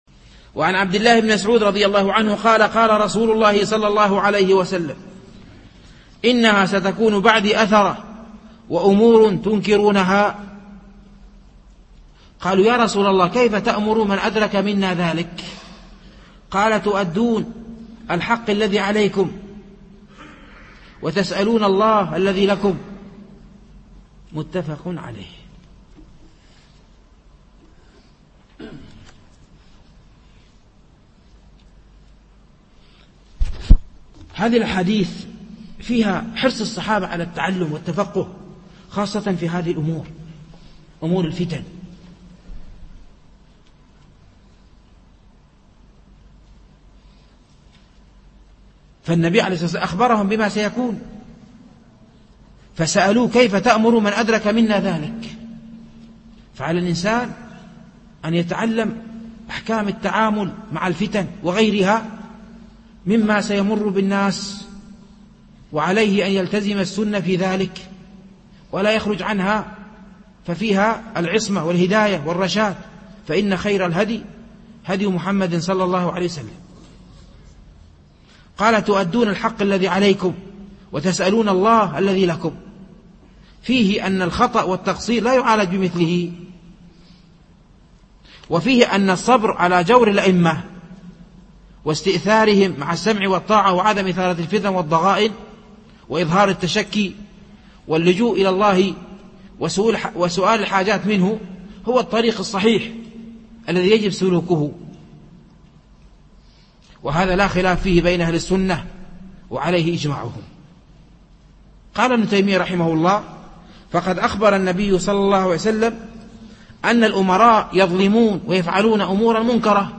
الألبوم: شبكة بينونة للعلوم الشرعية المدة: 5:55 دقائق (1.39 م.بايت) التنسيق: MP3 Mono 22kHz 32Kbps (VBR)